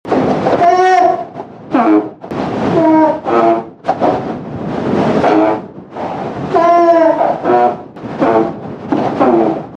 Alpaca Mom and Baby
Soft, maternal sounds typically made by: ✔ Mothers communicating with crias ✔ Females showing concern ✔ Animals expressing gentle interest
Alpaca-Mom-and-Baby.mp3